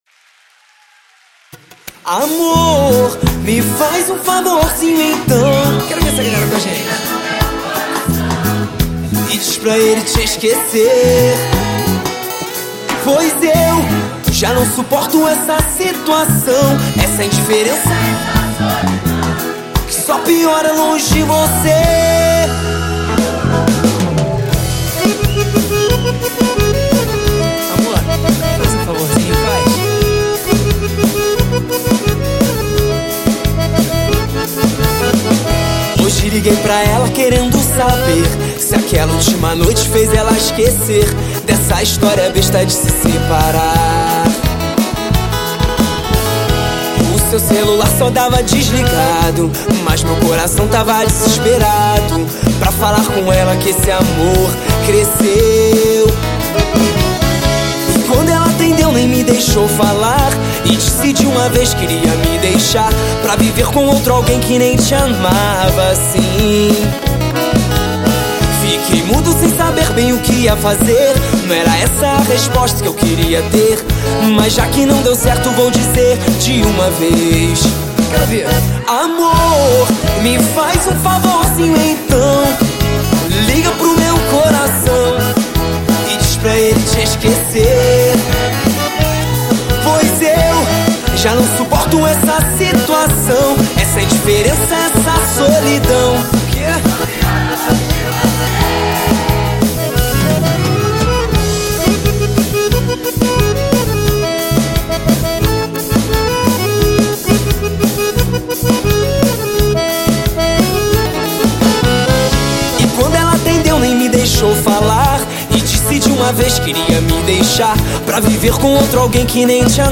• AO VIVO